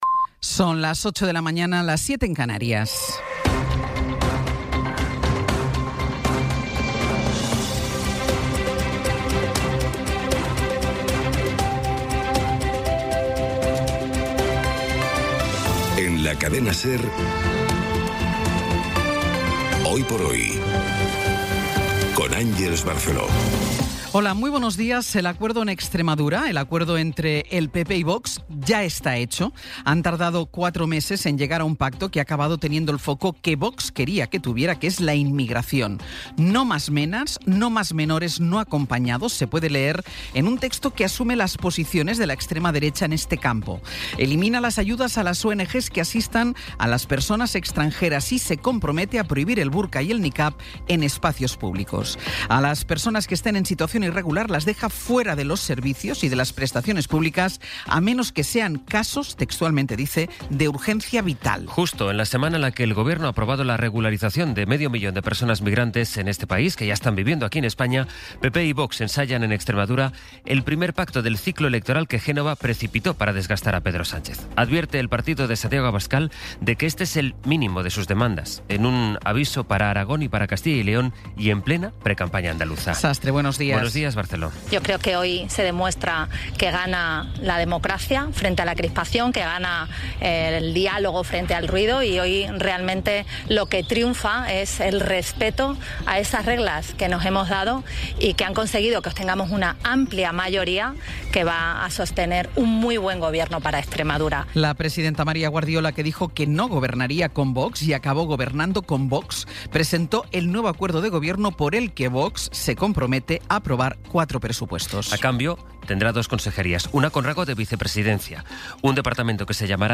Las noticias de las 08:00 20:04 SER Podcast Resumen informativo con las noticias más destacadas del 17 de abril de 2026 a las ocho de la mañana.